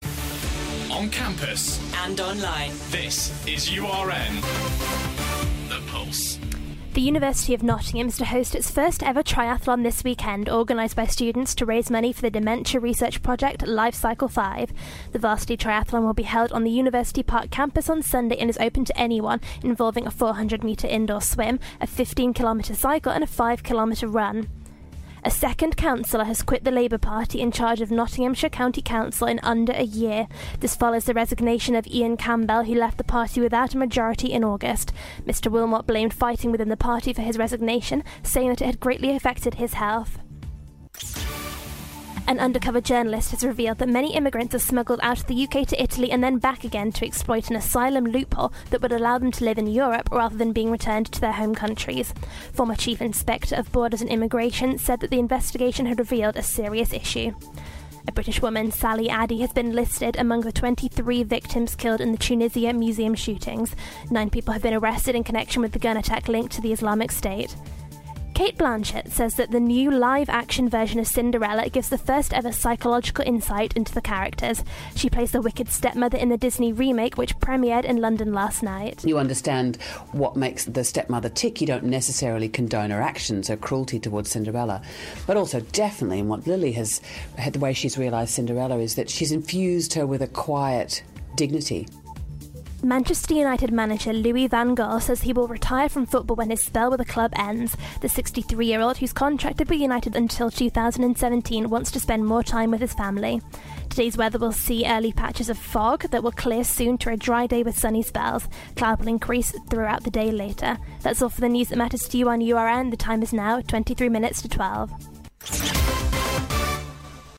Your Latest Headlines - Friday 20th March